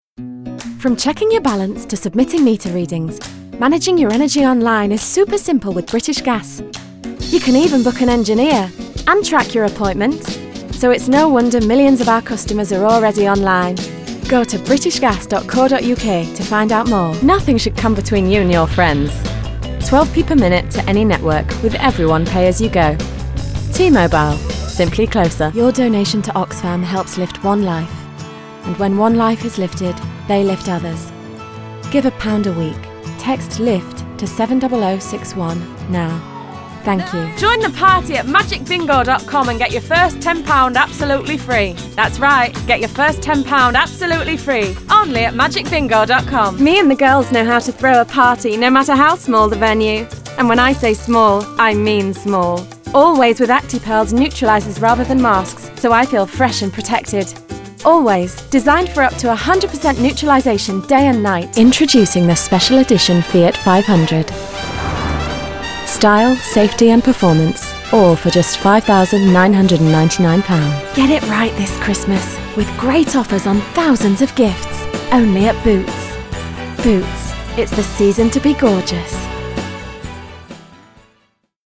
Northern bright, honest, wonderful opera singer home studio Spotlight CV Voicereel